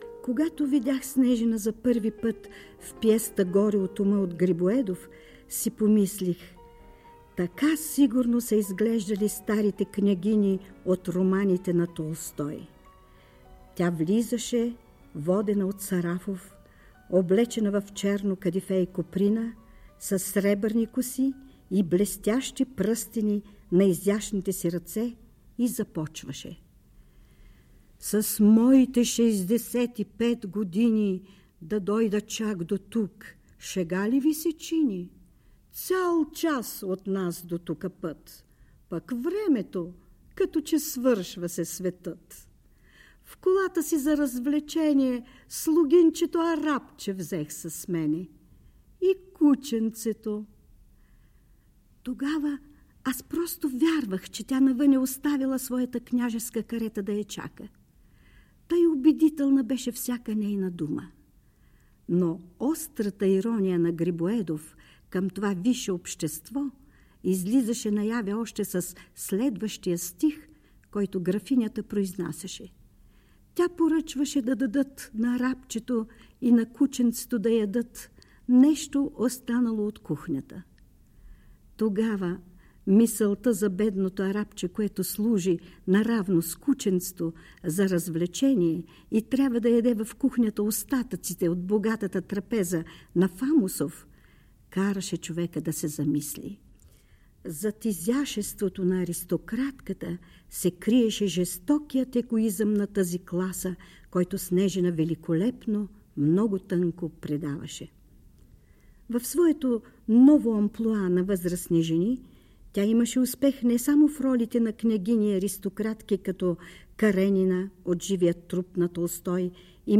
„В своето ново амплоа на възрастна жена в българските пиеси тя играеше с радост и готовност да помогне за развитието на българската драматургия“, допълва сценичните ѝ превъплъщения нейната близка приятелка на сцената и в живота актрисата Ирина Тасева. Запис от предаването „Календар на изкуството“ по БНР, посветено на 95-годишнината на Елена Снежина.